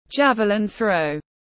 Môn ném lao tiếng anh gọi là javelin throw, phiên âm tiếng anh đọc là /ˈdʒævlɪn θrəʊ/
Javelin-throw-.mp3